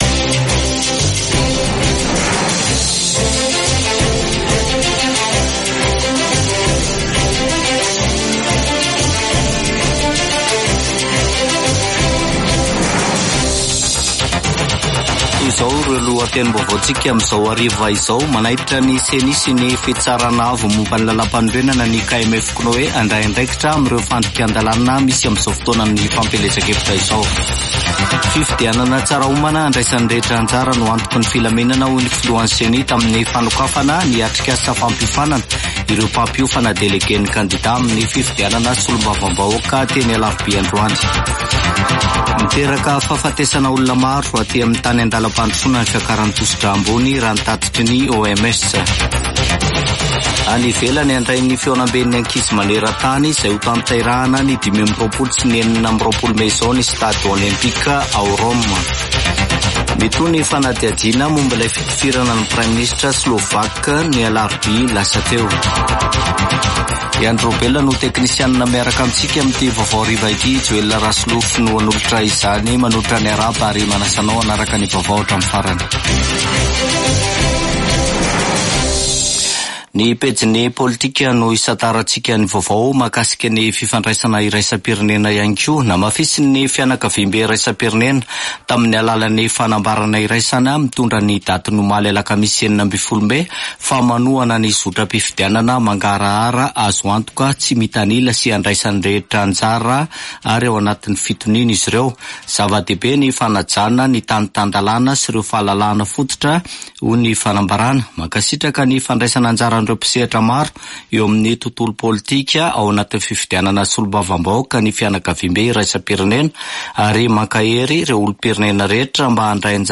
[Vaovao hariva] Zoma 17 mey 2024